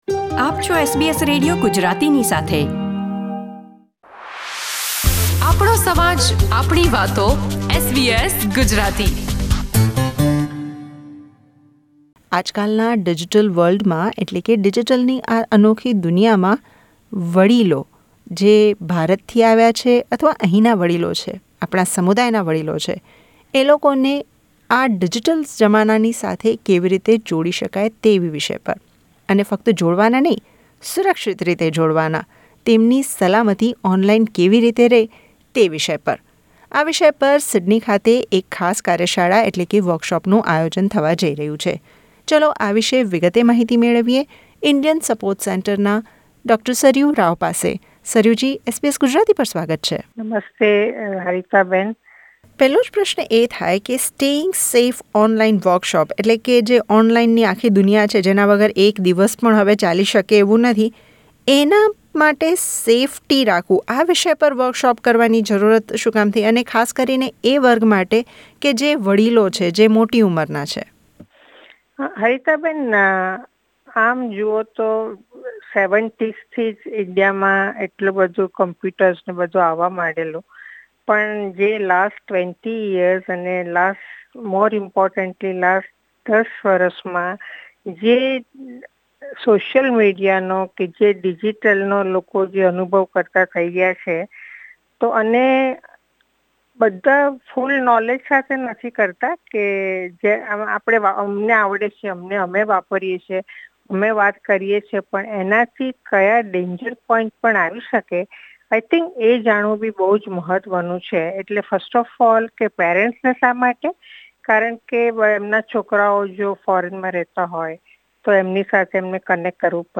મુલાકાત